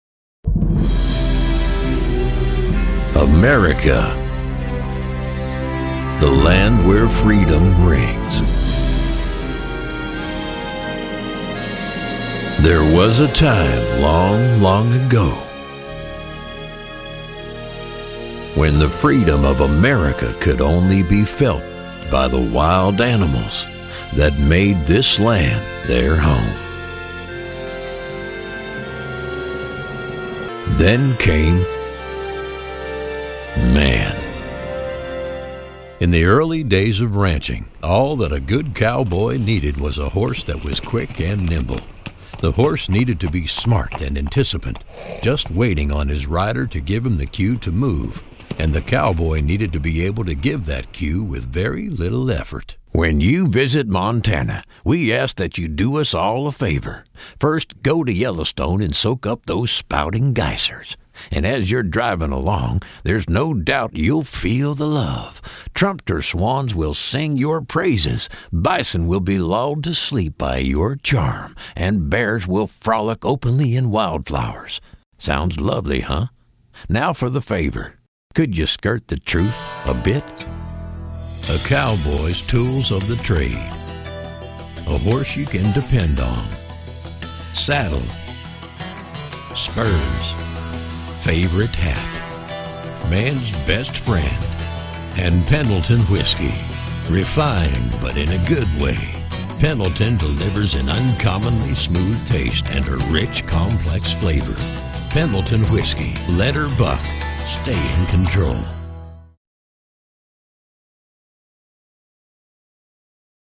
Americana, deep, warm, raspy, gravely, rural, authoritative, rich, real, conversational, rugged
middle west
Sprechprobe: Sonstiges (Muttersprache):